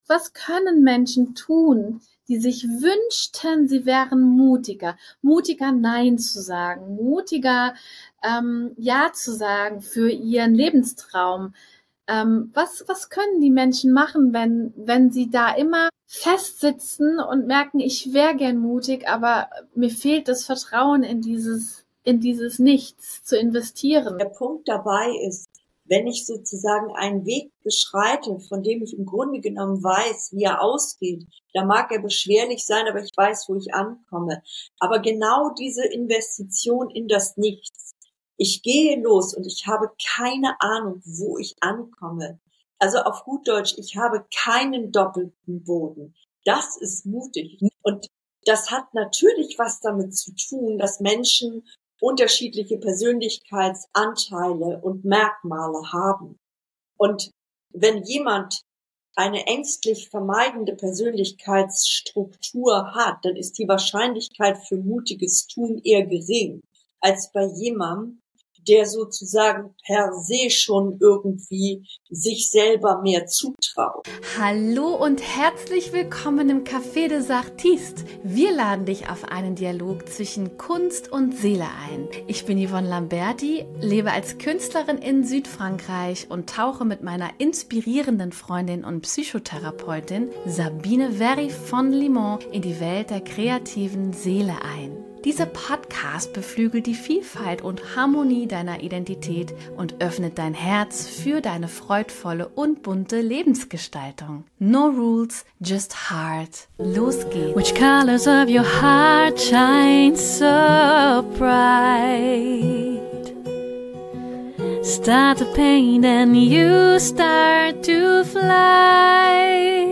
Ein Gespräch über Unsicherheiten, innere Konflikte, kreative Wege in die Tiefe – und die Kunst, weiterzugehen, obwohl man nicht weiß, wohin es führt.